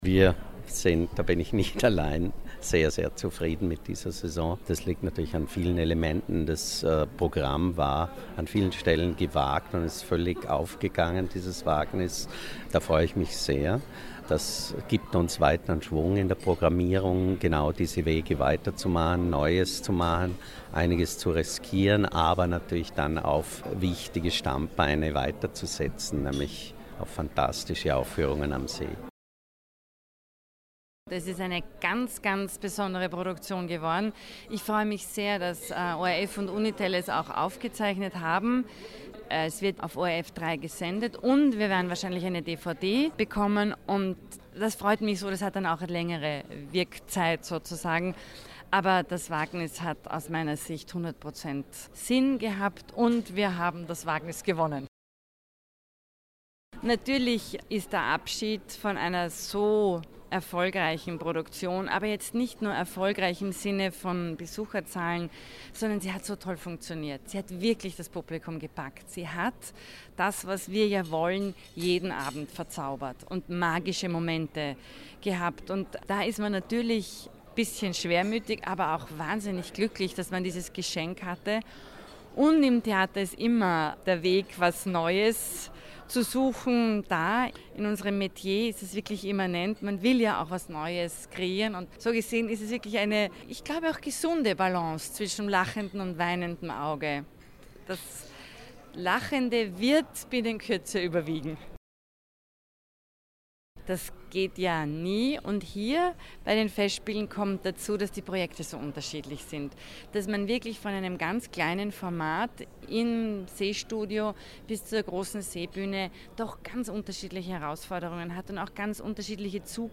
O-Ton Pressekonferenz Vorläufige Bilanz - Feature